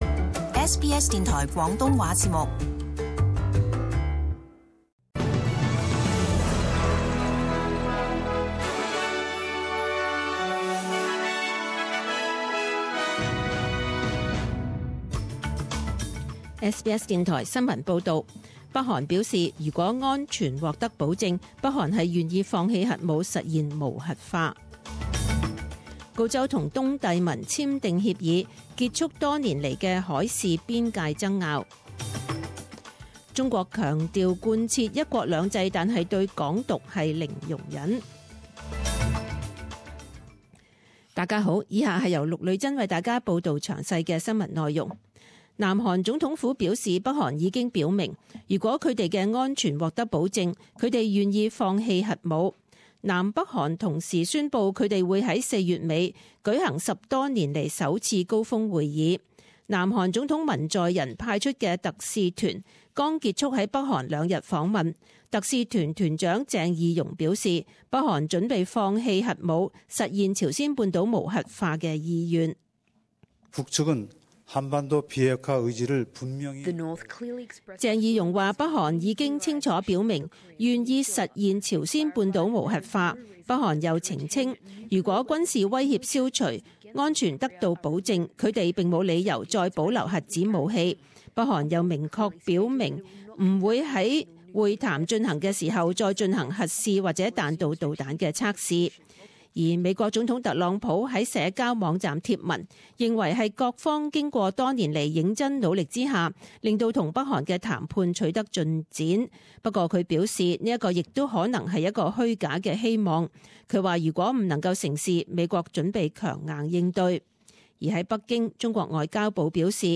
SBS中文新聞 （三月七日）